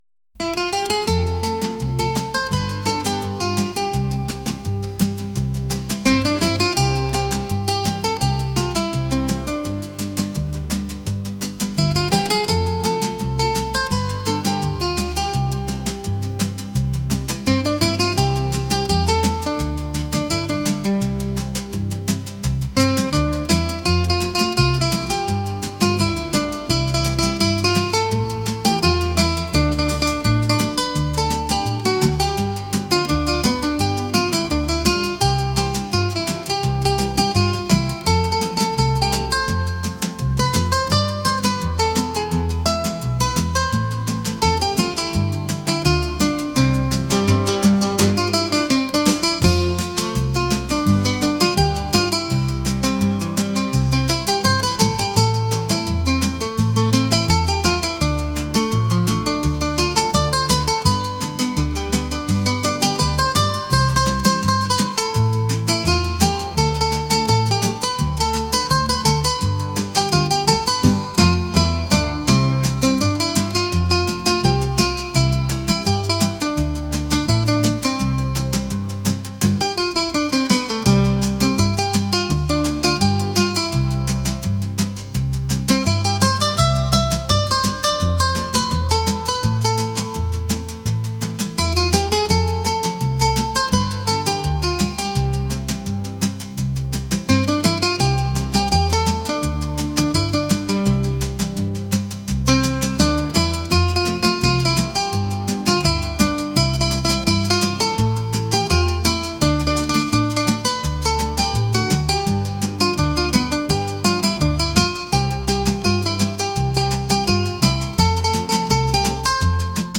pop | reggae